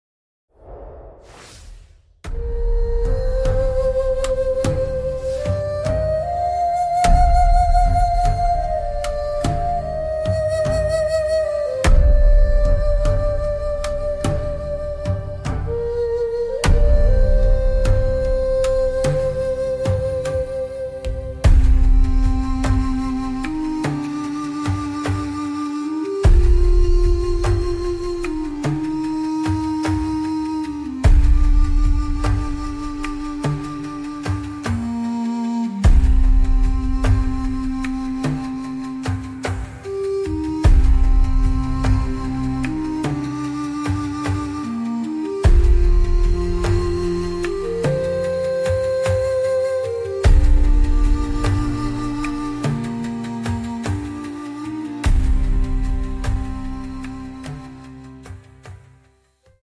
Traditional Japanese folk music for meditation and rest.